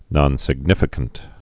(nŏnsĭg-nĭfĭ-kənt)